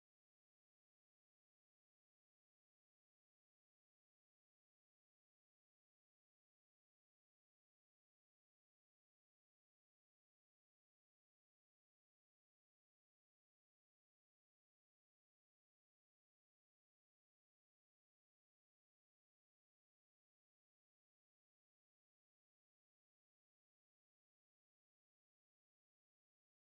minions spinning in slow motion sound effects free download